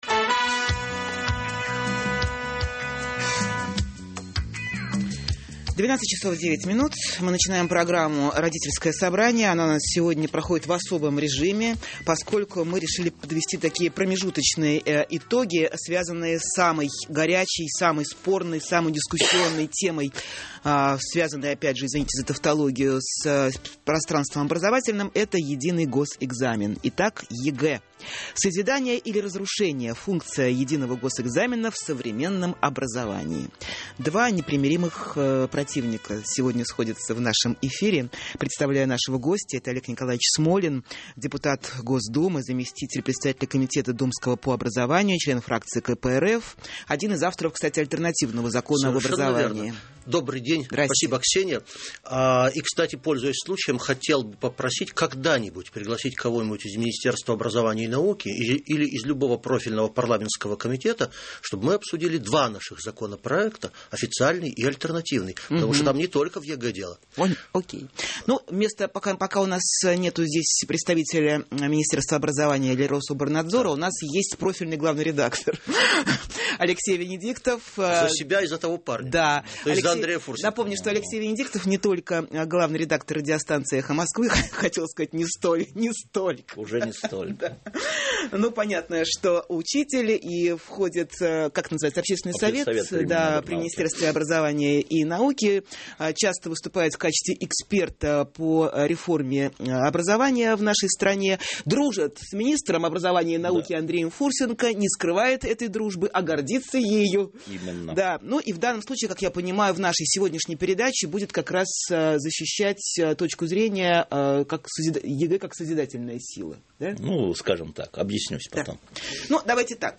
Особенность передачи в том, что на самом деле это «дуэль» между защищающим реформы в образовании главным редактором радиостанции А. Венедиктовым и их критикующим О. Смолиным.
Эфир 16 октября 2011 года, «Эхо Москвы»